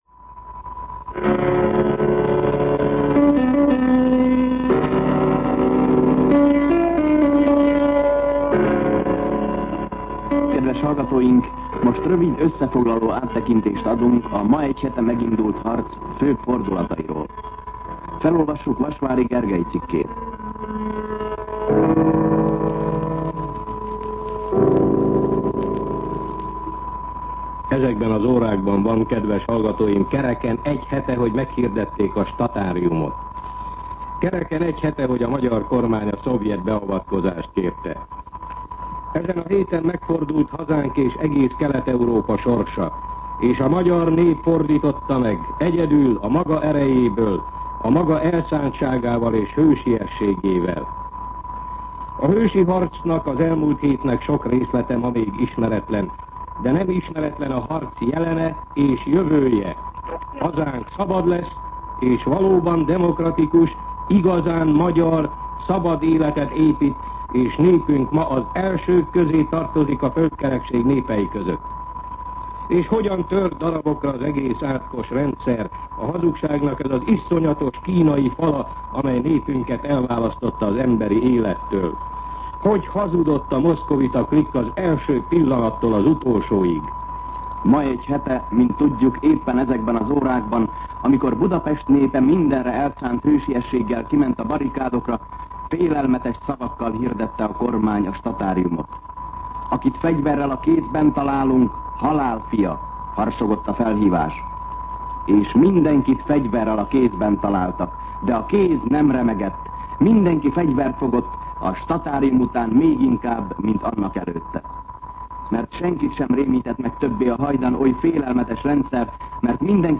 MűsorkategóriaKommentár
Megjegyzésmás adók hangja behallatszik